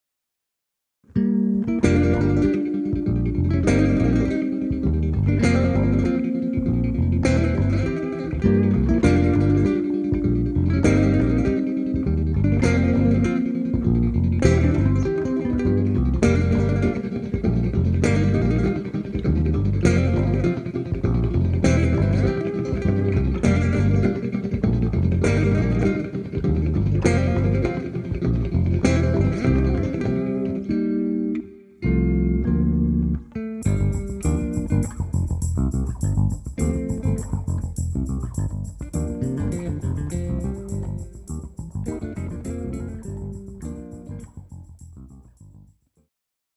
Vše kromě tamburiny a bicí soupravy hrají baskytary.